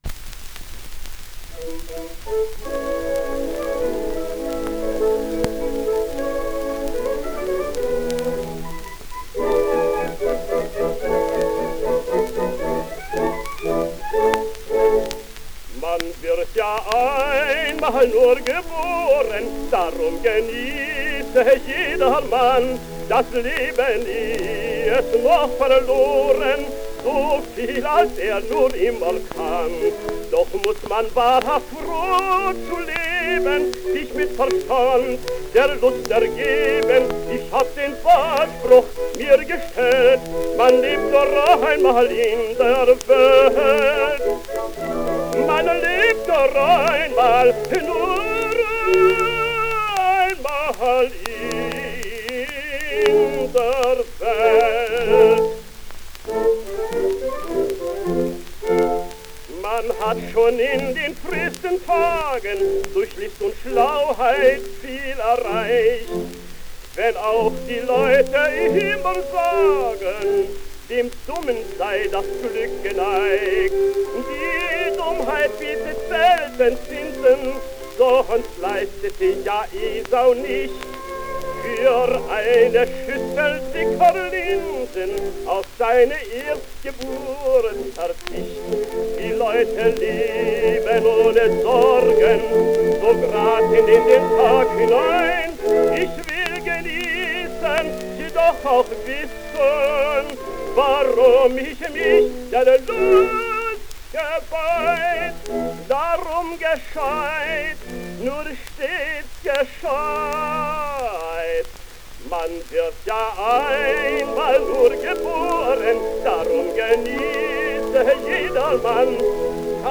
He made his debut in 1895 as Gomez (Das Nachtlager in Granada by Conradin Kreutzer) in Wrocław/Breslau. 1896 to 1900, he was a lyrical tenor at the opera in Köln, where he switched to the buffo repertoire.